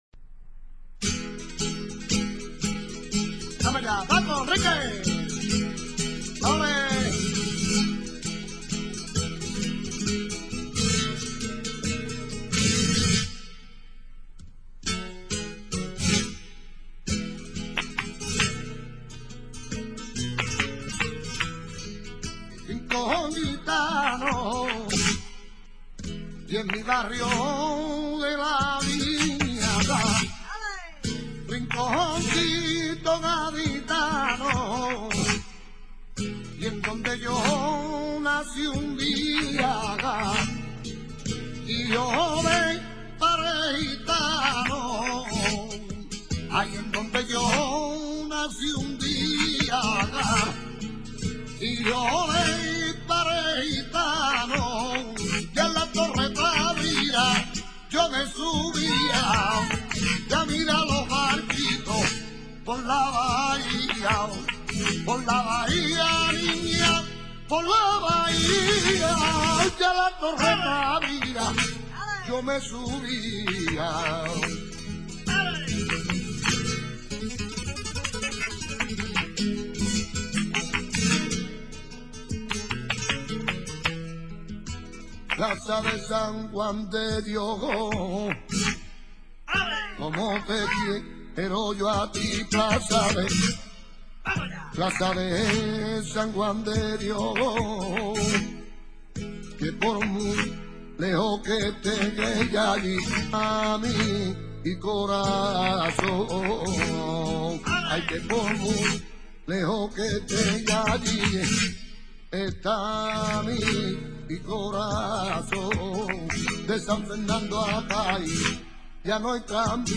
Naci� como cante para bailar, eminentemente festero. Se caracteriza por su dinamismo, desenvoltura y gracia.
alegrias.mp3